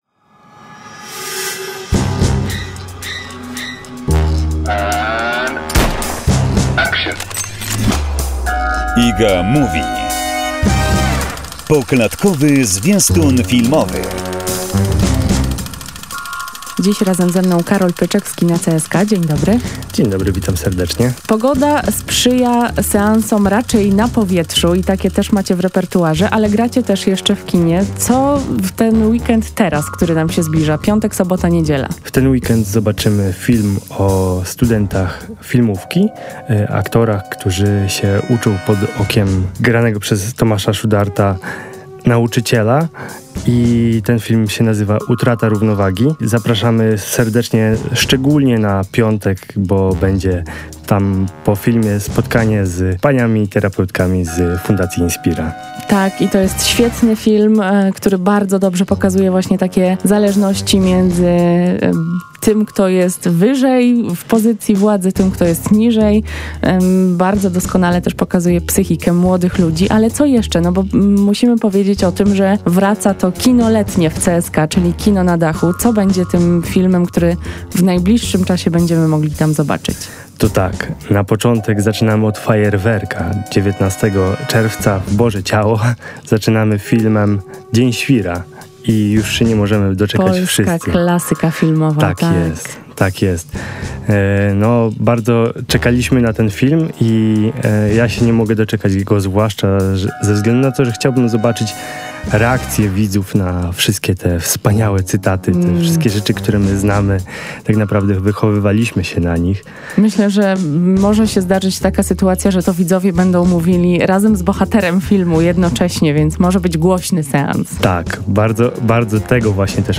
rozmowa o filmie „Pod powierzchnią” cz.5